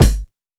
Kicks
Dilla Kick 19.wav